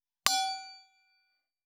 309金属製のワインカップ,ステンレスタンブラー,シャンパングラス,ウィスキーグラス,ヴィンテージ,ステンレス,金物グラス,
効果音厨房/台所/レストラン/kitchen食器